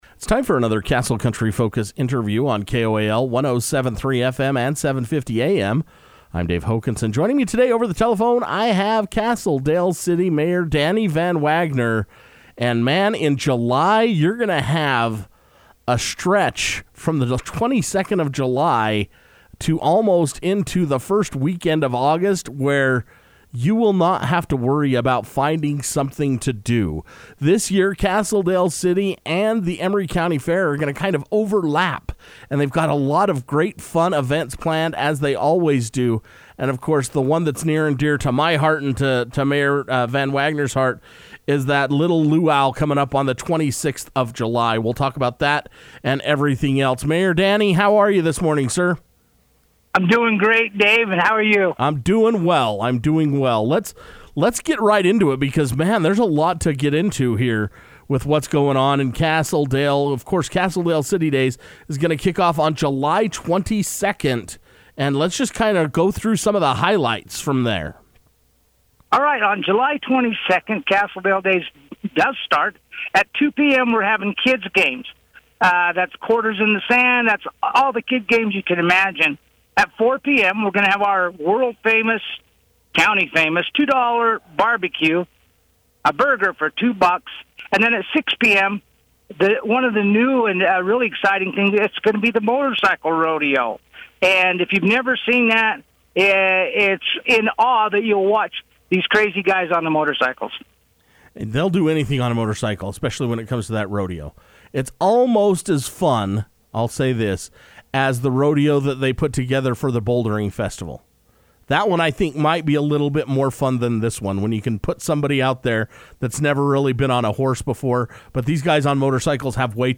Castle Dale City Mayor Danny VanWagoner talks Castle Dale City Days and Emery County Fair
It’s time once again for Castle Country Radio to catch up with Castle Dale City Mayor Danny VanWagoner about what is taking place in the city. He was so excited to announce that both Castle Dale City Days and Emery County Fair will run together this year making all the fun to be had in Castle Dale City in July.